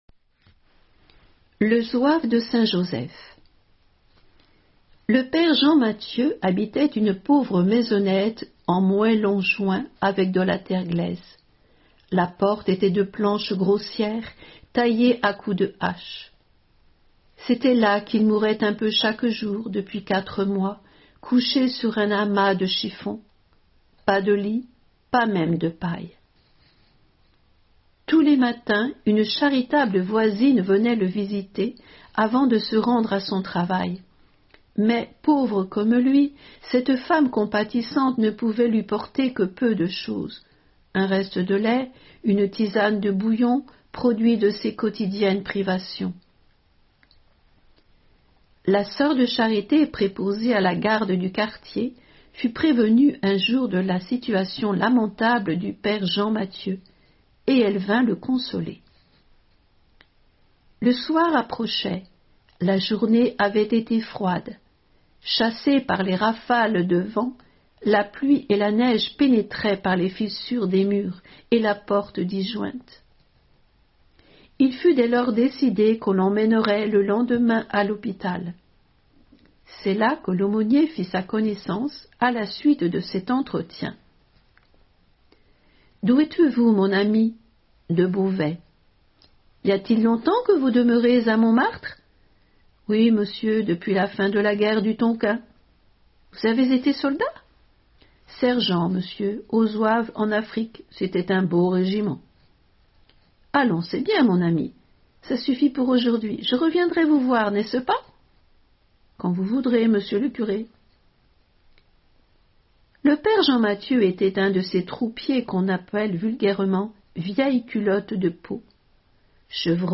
Lecture de vies de Saints et Saintes